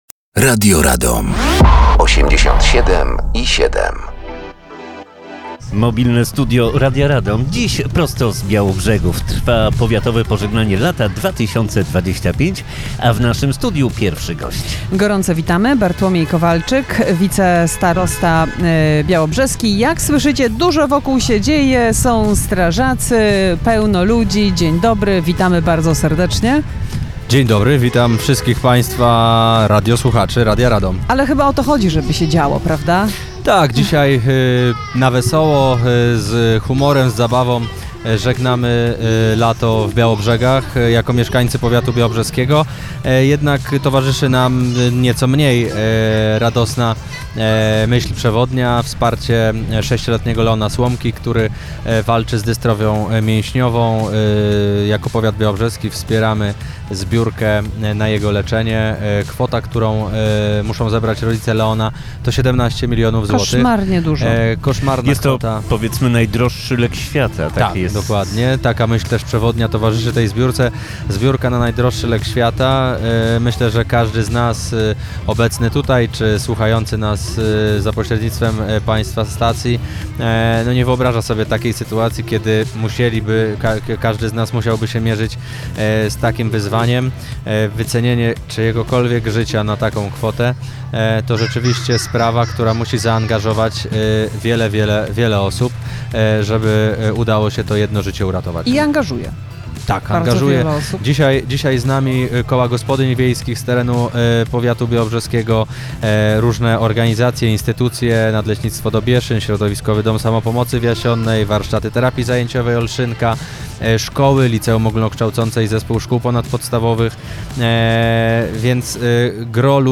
Powiatowe Pożegnanie Lata Białobrzegi 2025.
Dziś nasze Mobilne Studio nadaje z Białobrzegów.
Gościem Mobilnego Studia jest Wicestarosta Białobrzeski Bartłomiej Kowalczyk